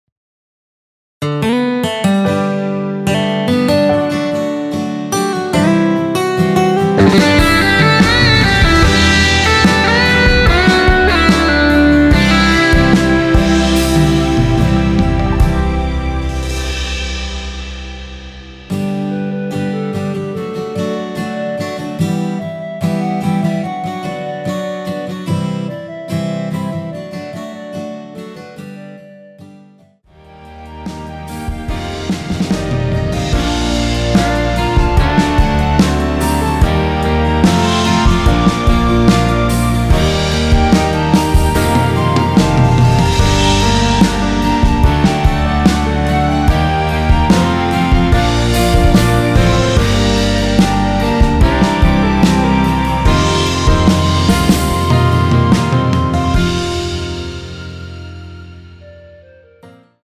원키(2절 삭제)멜로디 포함된 MR입니다.
앞부분30초, 뒷부분30초씩 편집해서 올려 드리고 있습니다.
중간에 음이 끈어지고 다시 나오는 이유는